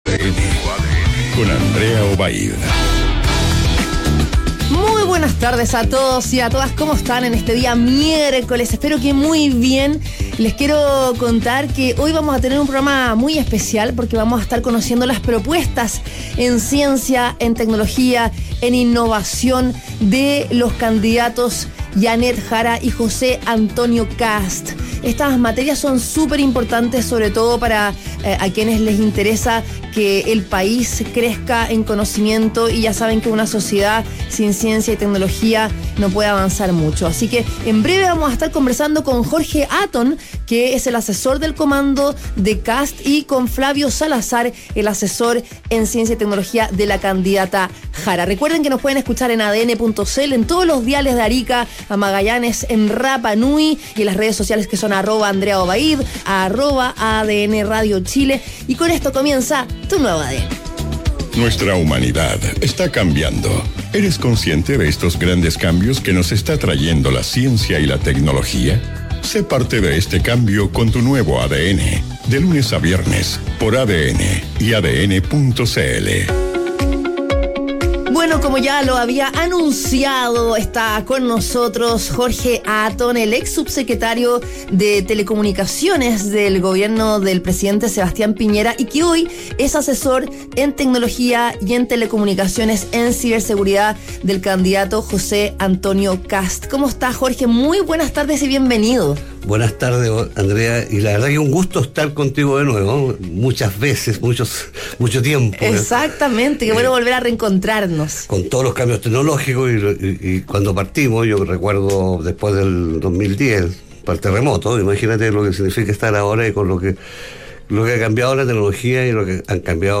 En conversación con Tu Nuevo ADN, Jorge Atton, asesor de temas de tecnología de José Antonio Kast, explicó cómo, en un eventual gobierno del candidato republicano, la inteligencia artificial y la innovación jugarán un rol central.